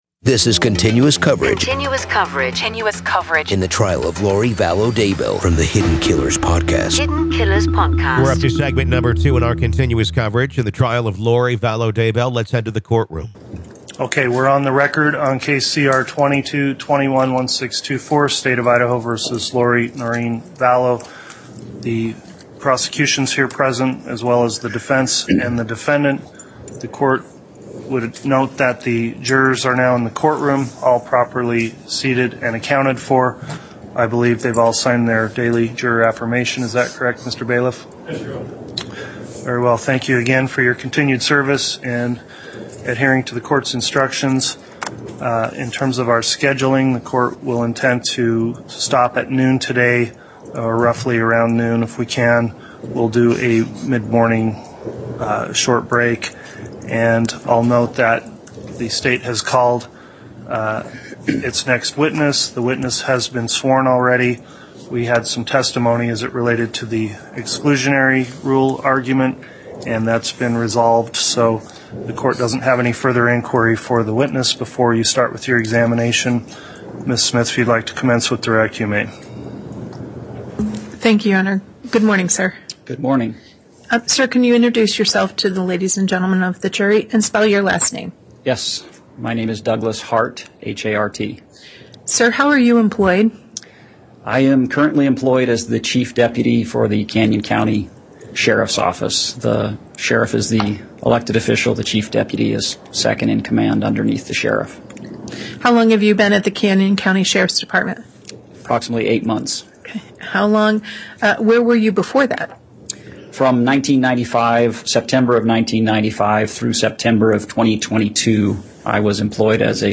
With raw courtroom audio, and interviews from experts and insiders, we analyze the evidence and explore the strange religious beliefs that may have played a role in this tragic case.